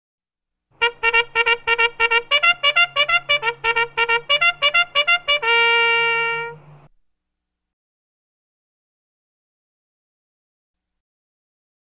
B Trumpet